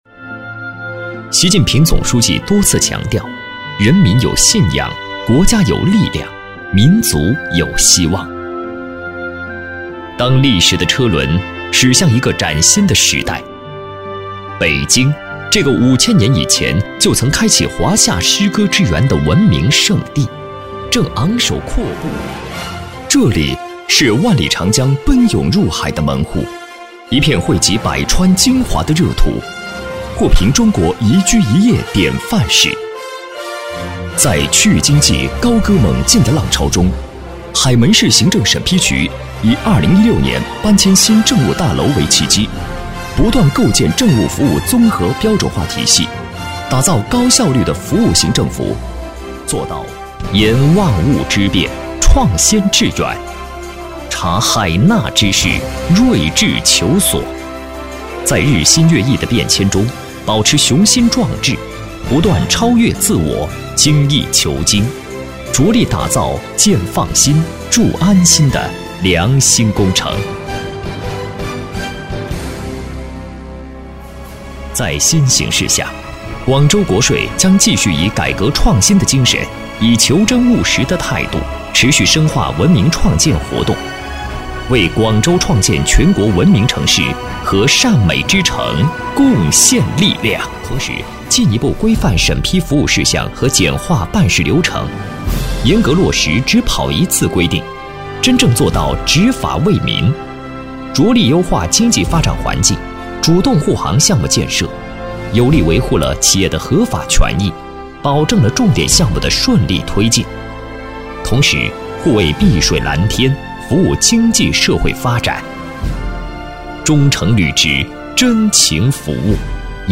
毕业于中国传媒大学播音主持专业，从事配音行业数年，普通话一级甲等水平，全能型风格加之高端的品质，让他的声音得到观众和业内的认可。